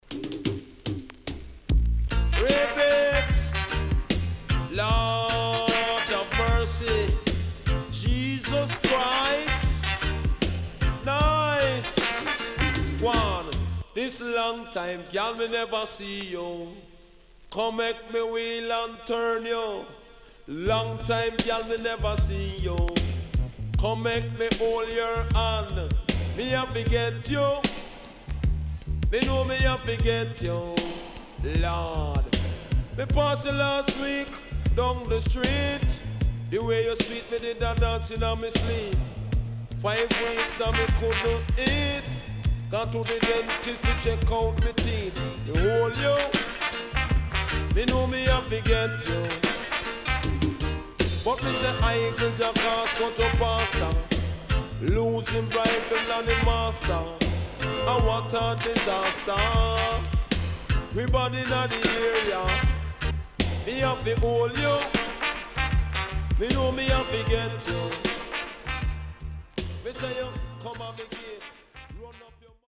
HOME > DANCEHALL
riddim
SIDE B:少しチリノイズ入りますが良好です。